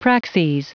Prononciation du mot praxes en anglais (fichier audio)